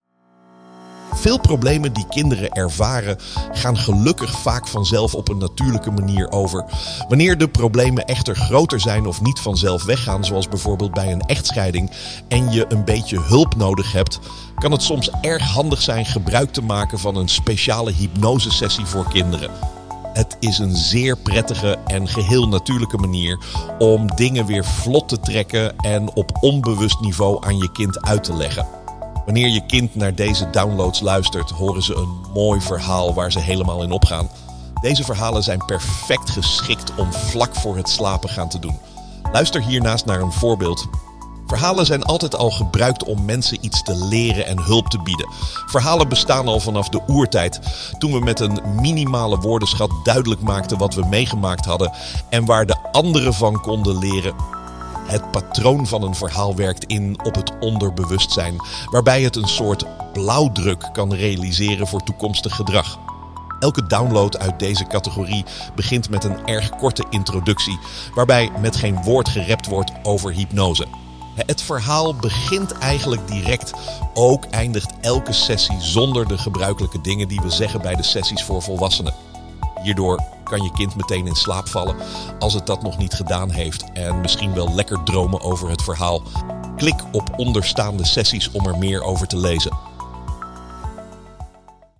Vriendelijke hypnotische verhalen om je meest waardevolle kleine gezinslid te helpen.
hypnose-voor-kinderen-new.mp3